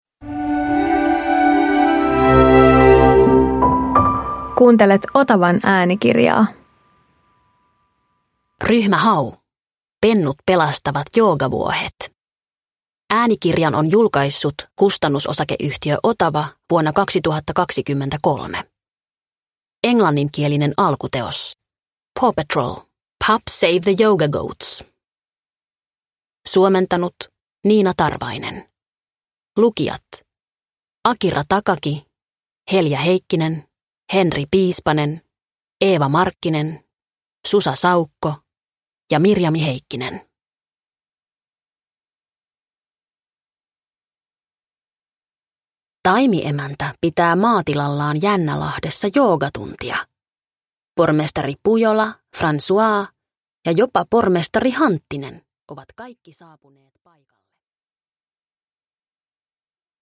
Ryhmä Hau - Pennut pelastavat joogavuohet – Ljudbok – Laddas ner